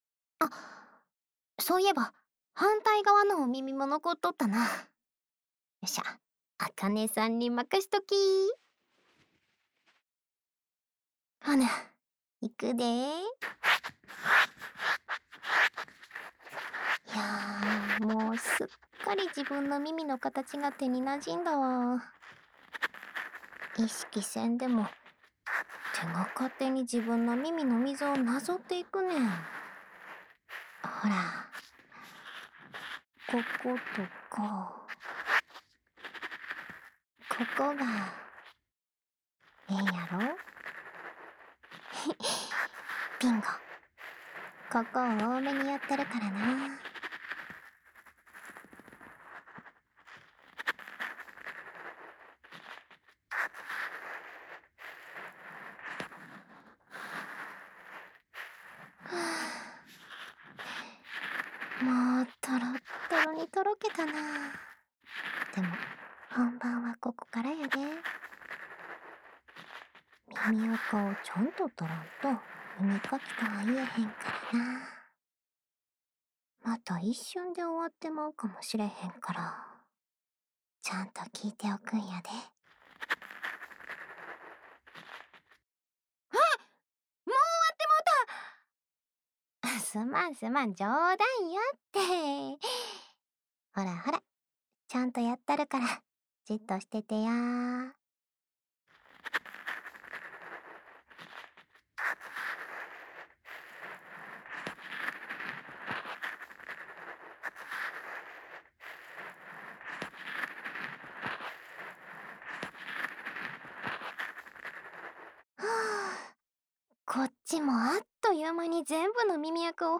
日常/生活 治愈 关西腔 掏耳 环绕音 ASMR 低语